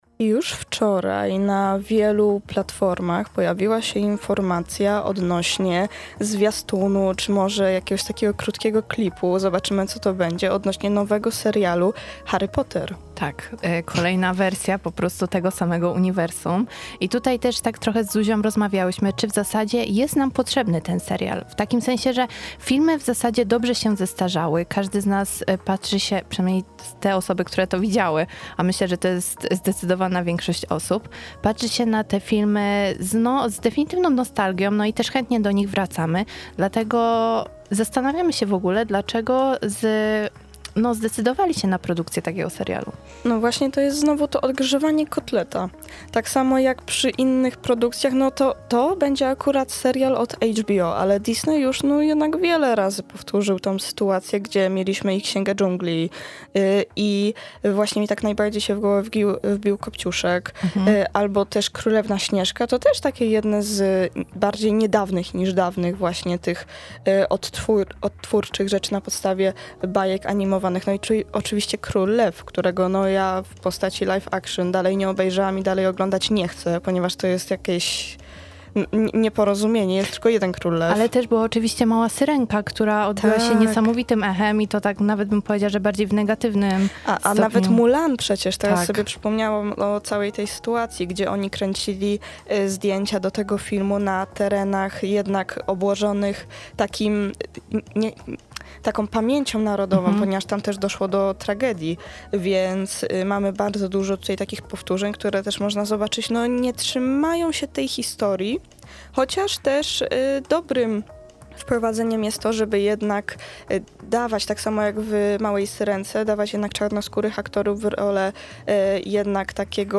Rozmowa miała miejsce podczas środowej edycji Pełnej Kultury.